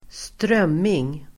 Ladda ner uttalet
Uttal: [²str'öm:ing]